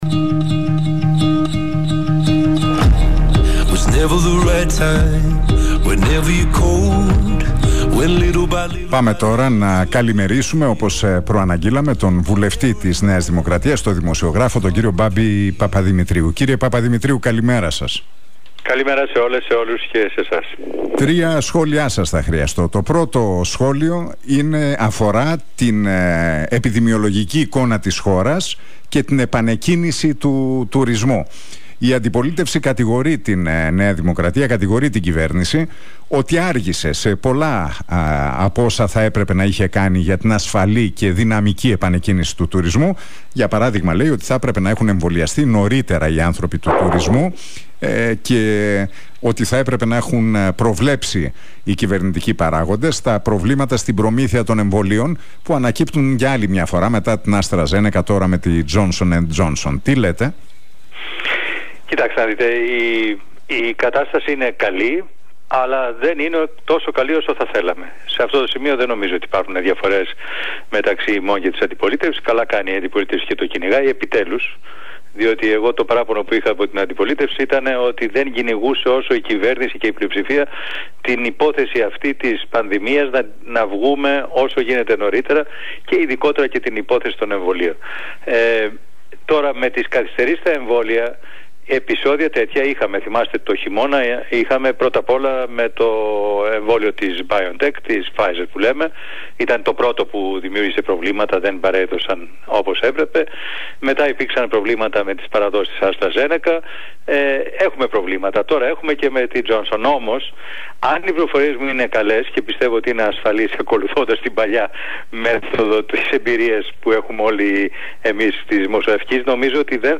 Ο βουλευτής της ΝΔ, Μπάμπης Παπαδημητρίου, μιλώντας στον Realfm 97,8 και στην εκπομπή του Νίκου Χατζηνικολάου...